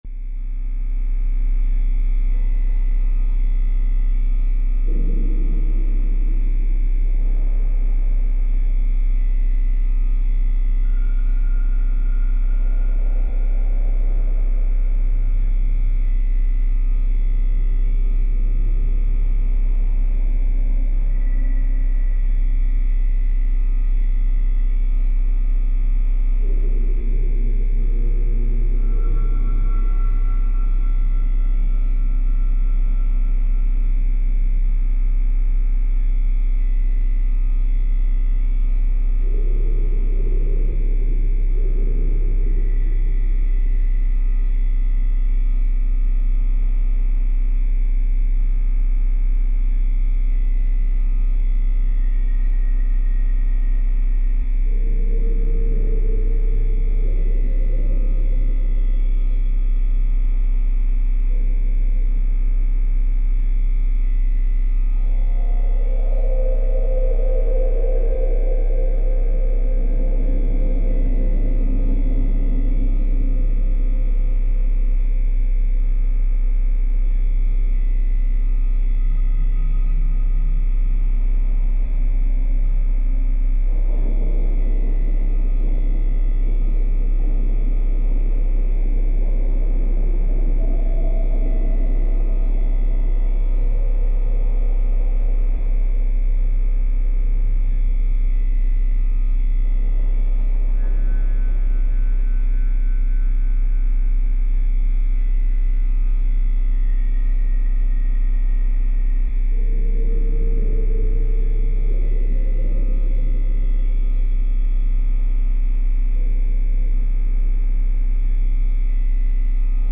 Погрузитесь в тревожную атмосферу Закулисья с нашей коллекцией звуков. Скачивайте или слушайте онлайн жужжание флуоресцентных ламп, отдаленные шаги в пустых коридорах, непонятный шепот и другие аудио-сигналы из разных уровней.